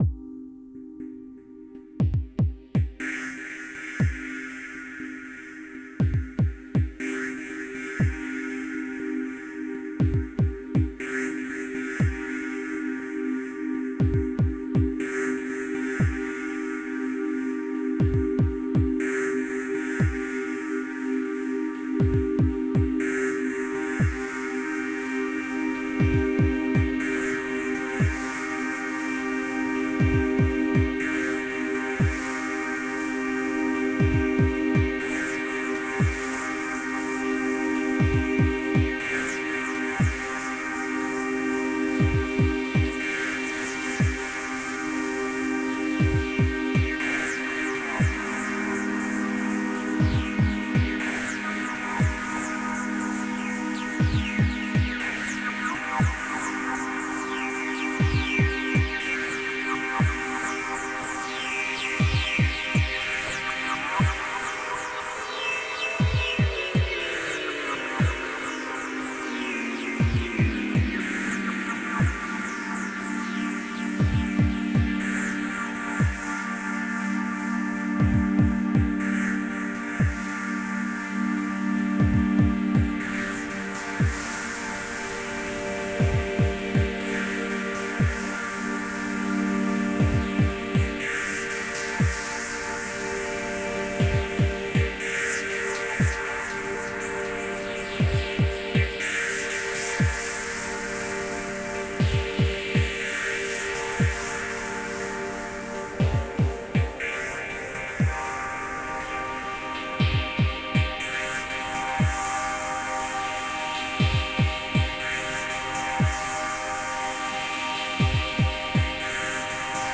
Another pack of samples, hand made from the Circuit Novation Synth. Darker, industrial sounds.
Drums, Synths and more!